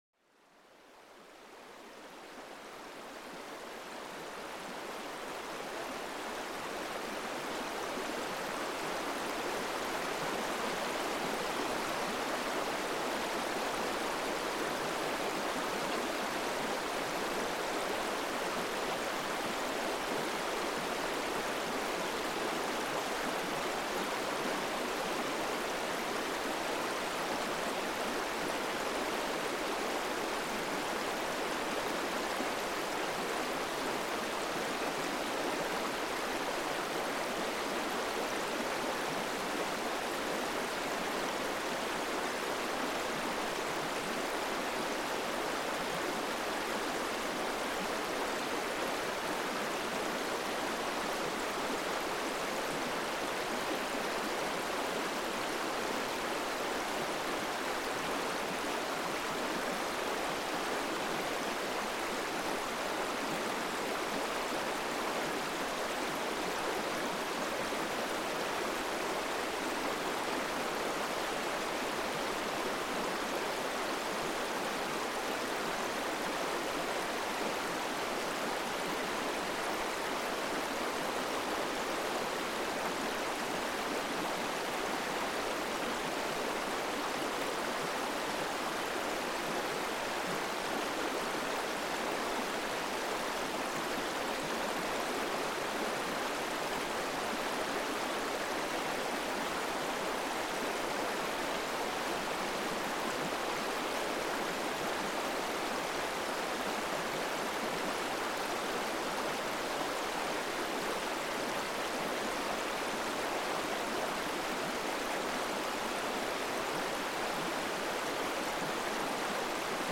Le courant d'une rivière : Harmonie naturelle pour apaiser l'esprit